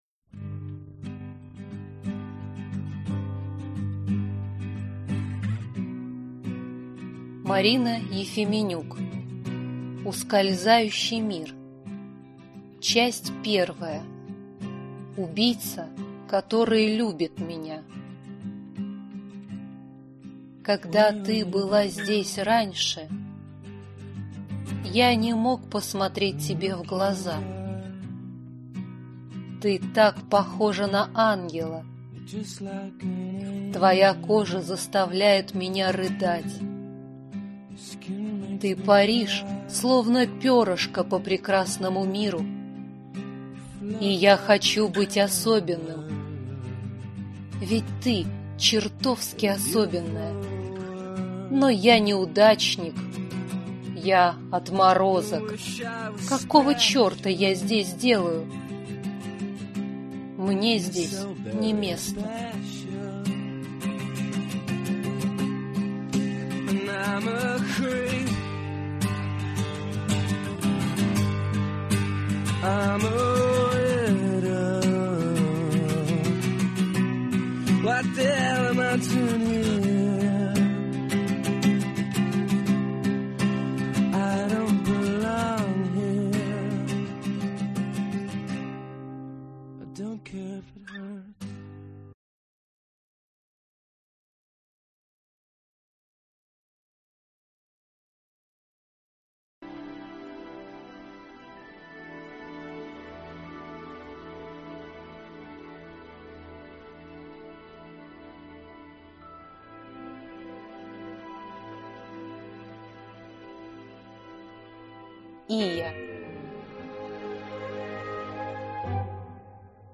Аудиокнига Ускользающий мир | Библиотека аудиокниг